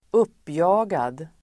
Ladda ner uttalet
Uttal: [²'up:ja:gad]